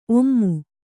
♪ ommu